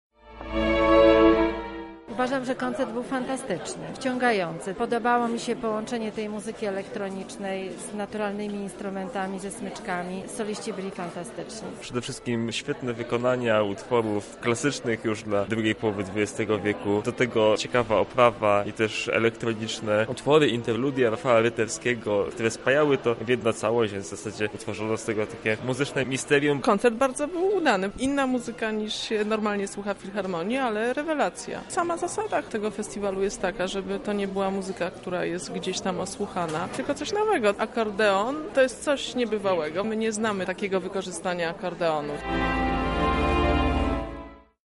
zapytał uczestników o wrażenia po widowisku: